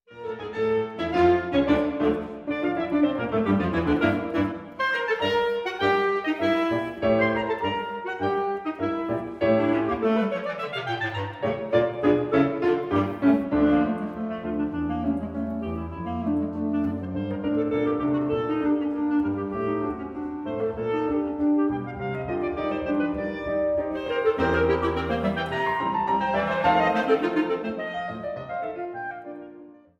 Klarinette
Bassetthorn und Klarinette
Klavier